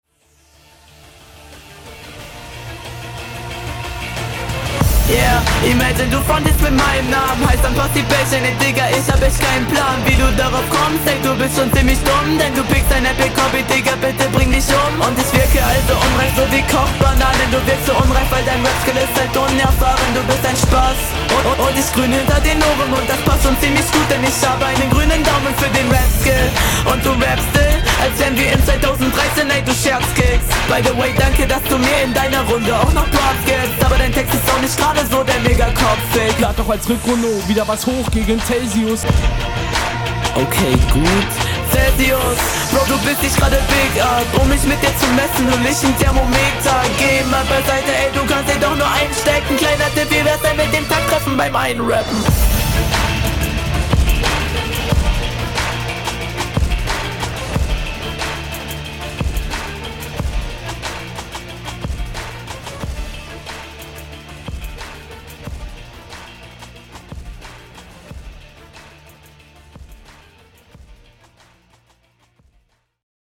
Flow: Flow ist onpoint, ich konnte auch keine wirklichen flow fehler raushören.